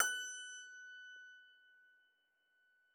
53l-pno20-F4.wav